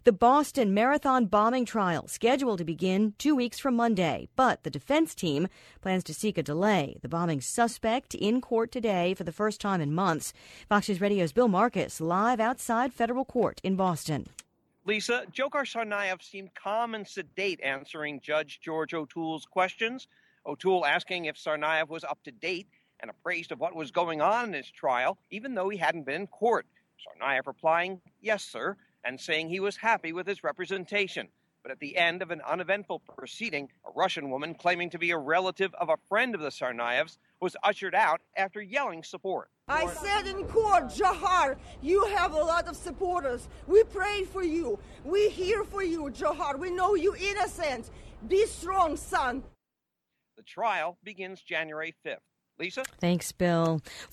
1pm Live